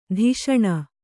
♪ dhish'aṇa